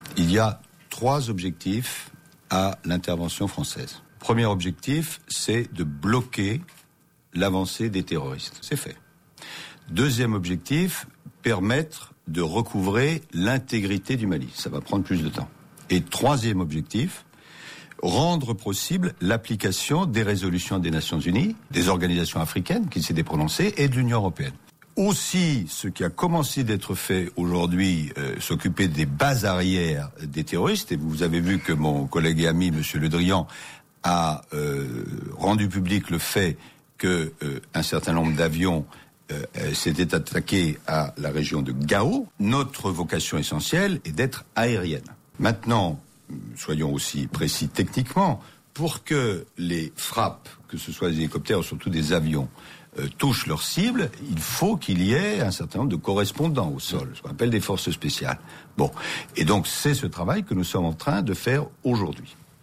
Laurent Fabius, ministre français des Affaires étrangères, interrogé sur RTL